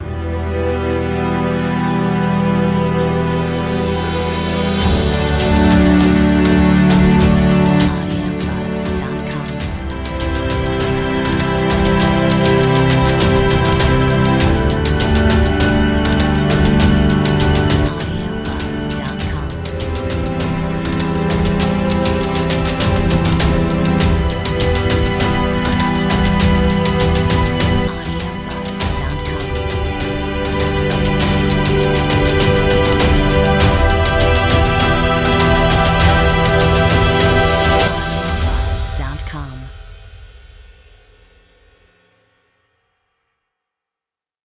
Metronome 100